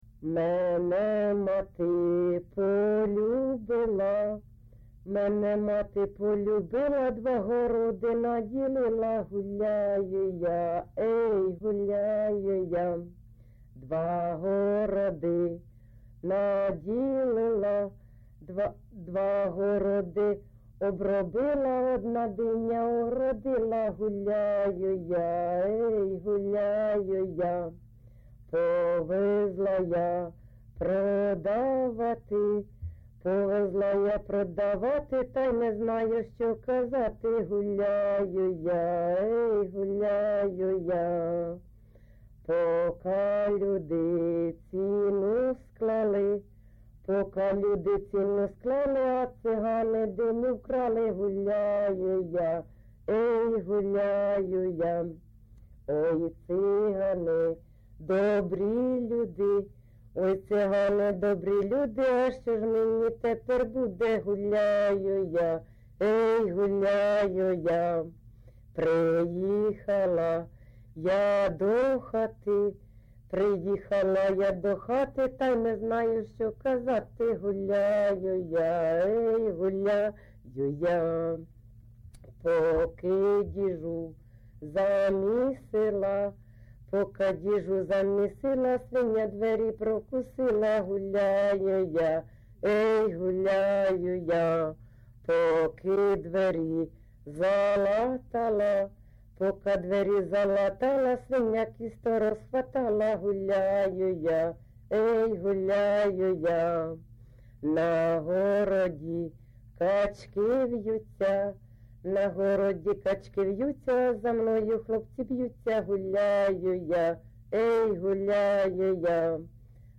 ЖанрЖартівливі
Місце записус. Оленівка Волноваський район, Донецька обл., Україна, Слобожанщина